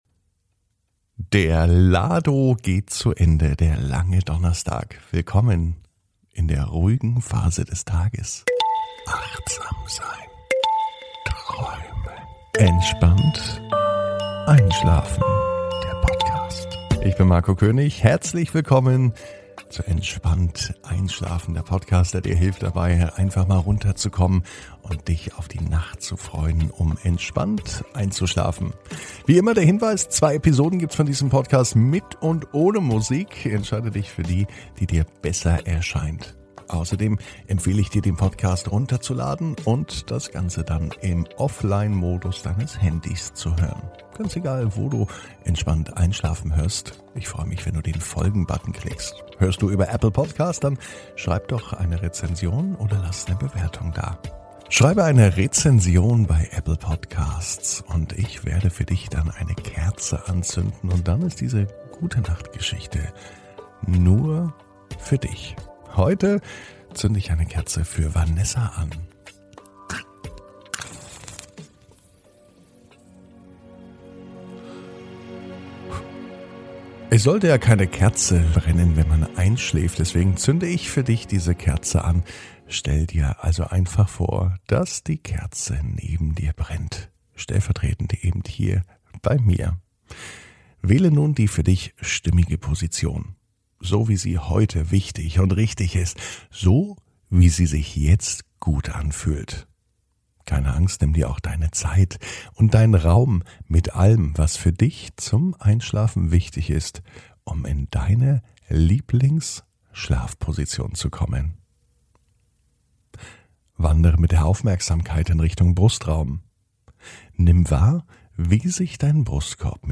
(ohne Musik) Entspannt einschlafen am Donnerstag, 03.06.21 ~ Entspannt einschlafen - Meditation & Achtsamkeit für die Nacht Podcast